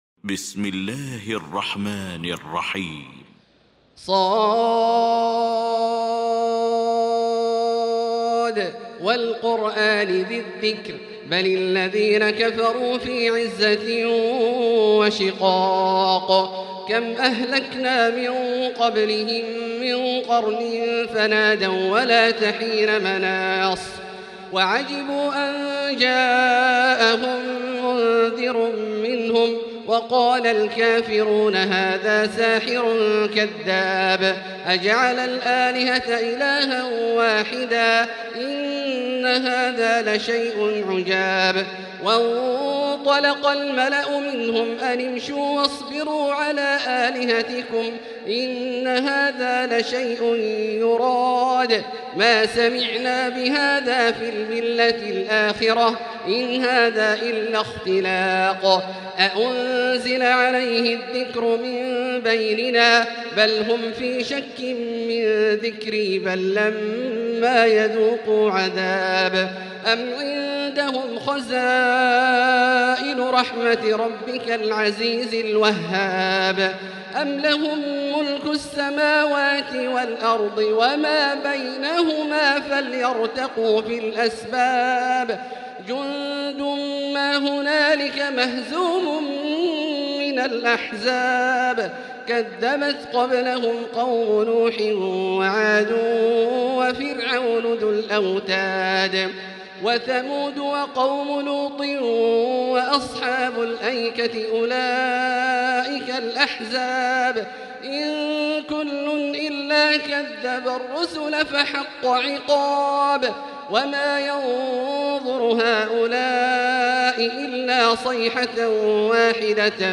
المكان: المسجد الحرام الشيخ: فضيلة الشيخ عبدالله الجهني فضيلة الشيخ عبدالله الجهني ص